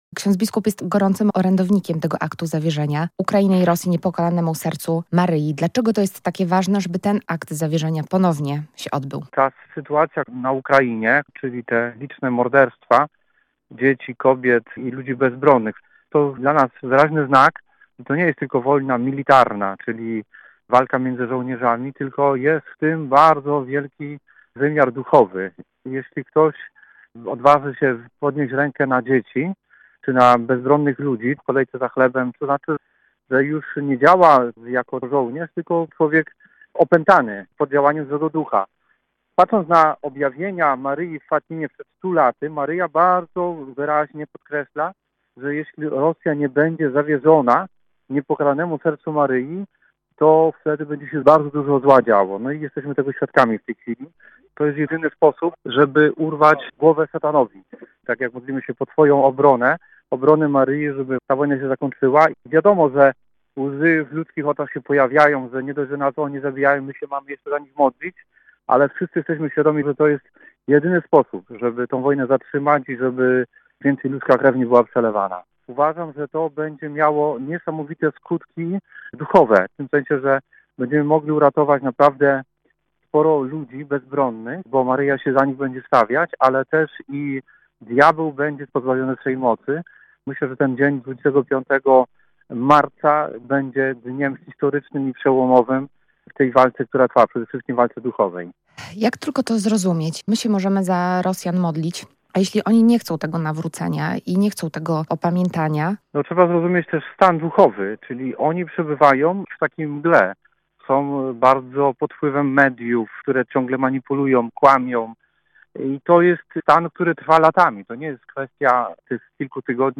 biskup pomocniczy archidiecezji lwowskiej